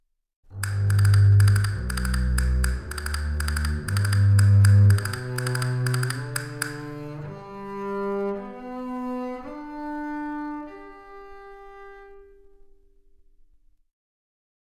Mix of a castanets and double bass signal.